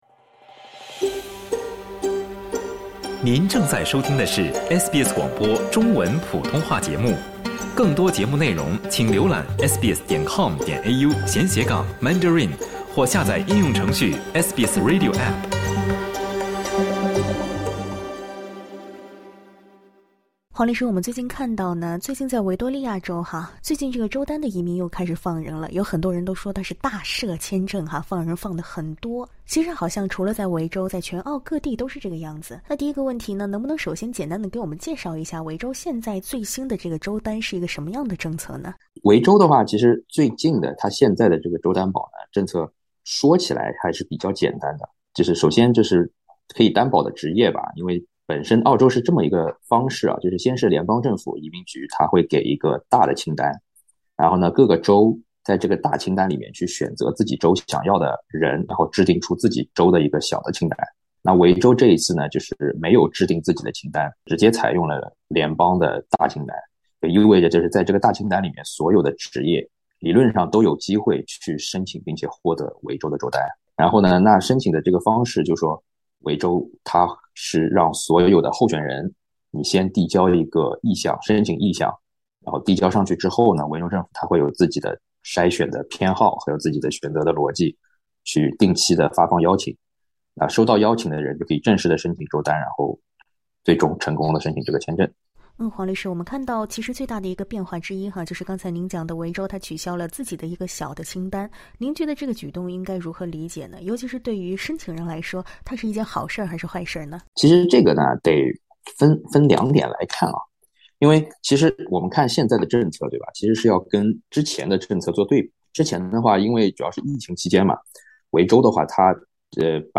“大赦”签证的说法到底合不合理？背后的原因是什么？申请人又该采取什么行动呢？（点击上方音频收听采访）